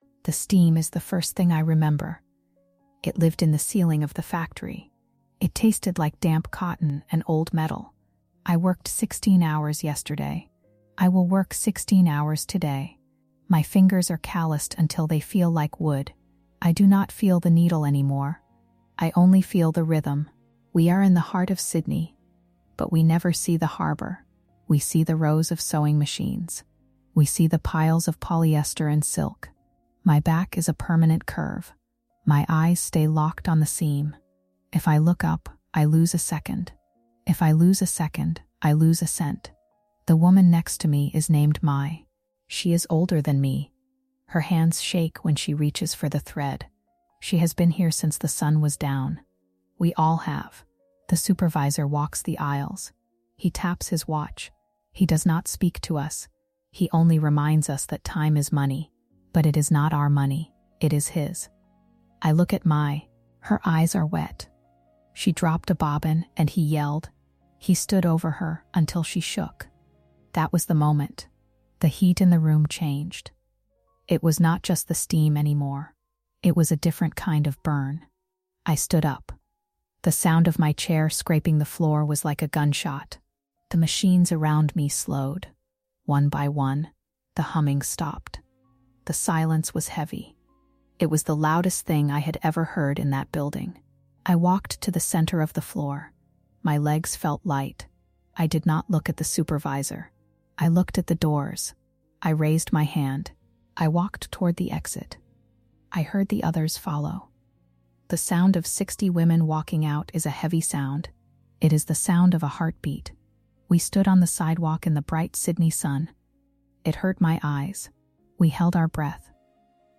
This first-person narrative follows a Vietnamese-Australian factory worker who leads a desperate walkout against 16-hour shifts and sub-legal wages, only to face a brutal "Power Flip" when her employer weaponizes her visa status as an act of retaliation.
This storytelling podcast episode serves as a powerful testament to the invisible women behind the "Made in Australia" labels, highlighting the moral compromises and systemic failures that govern their lives.